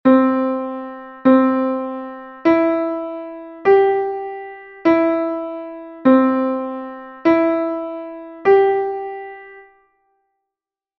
G,C and E note recognition exercise 3
note_recognition_3.mp3